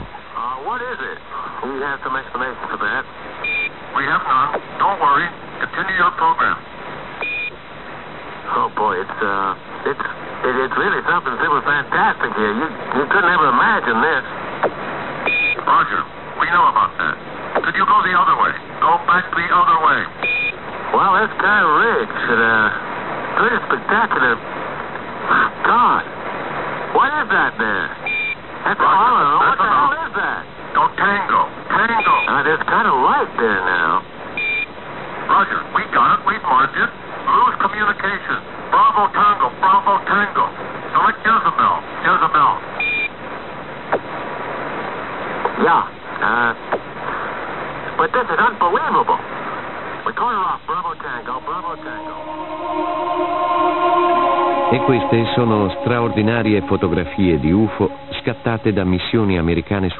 Apollo 11 Text dramatized - 1969